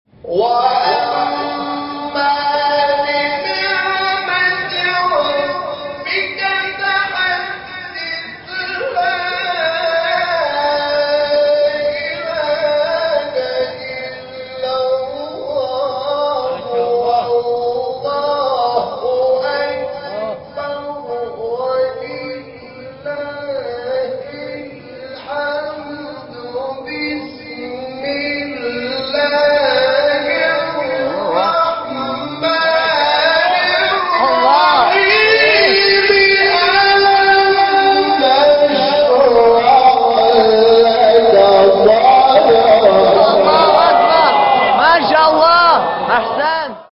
سوره : انشراح آیه : 1 استاد : حامد شاکرنژاد مقام : مرکب‌خوانی( چهارگاه * نهاوند) قبلی بعدی